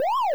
siren1.wav